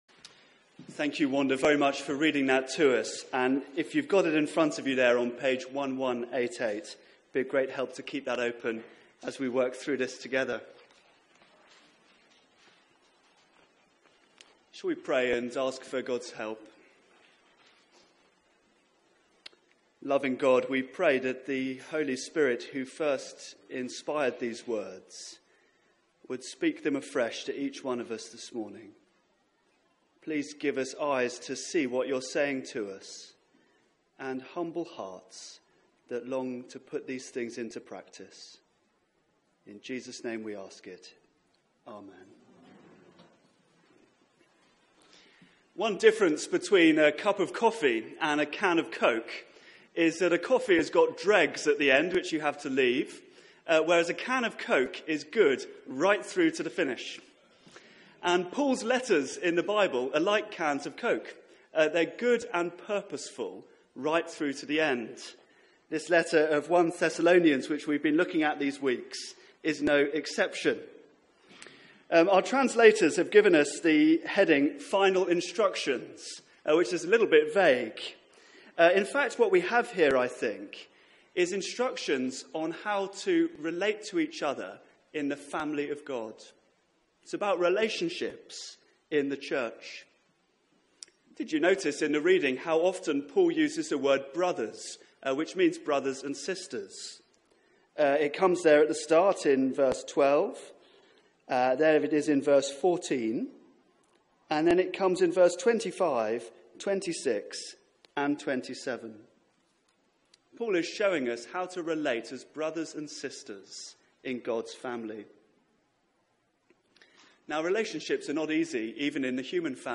Media for 9:15am Service on Sun 25th Jun 2017
Theme: Real Christian Relationships Sermon